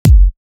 Raze Sizzle.wav